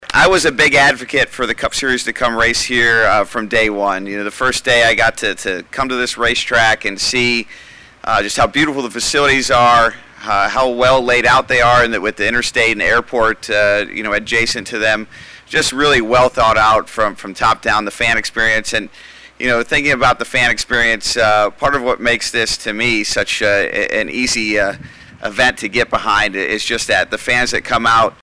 NASCAR racer Brad Keslowski was also on hand for the announcement. He has raced and won at the Iowa Speedway.